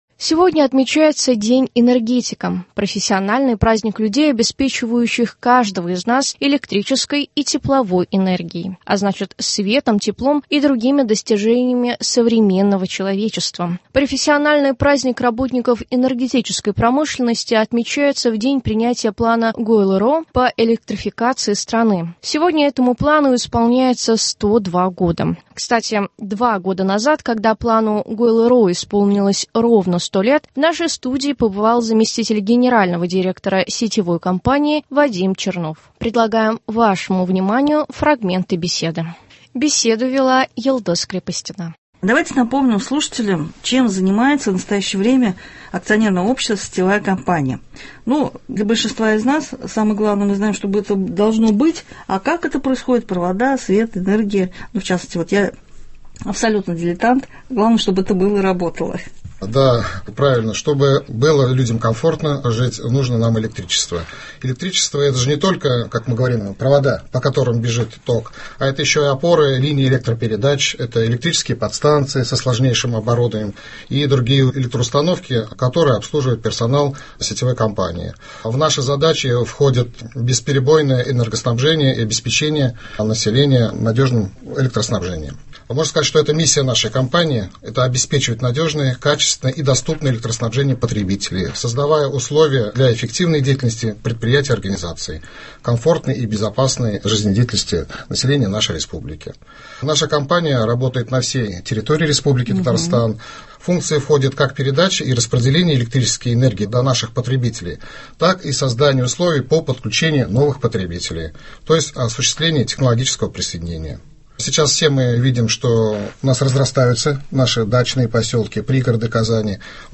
Предлагаем вашему вниманию фрагменты беседы.